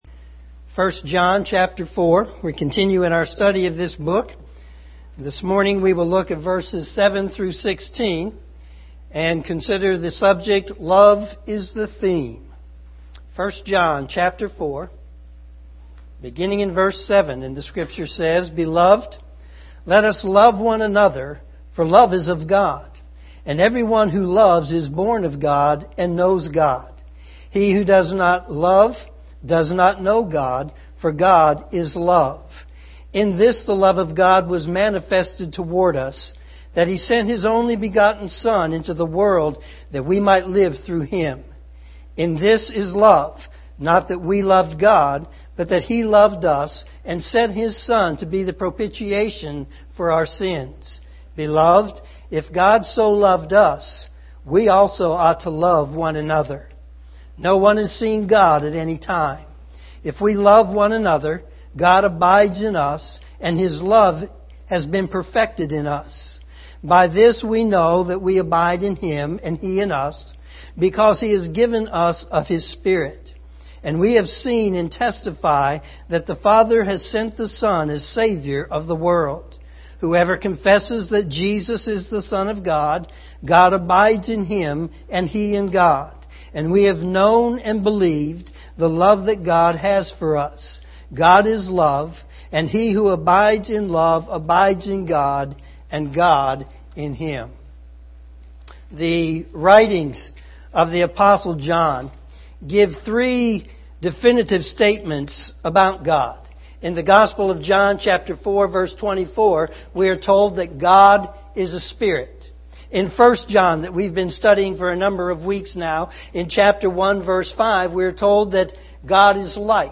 Morning Sermon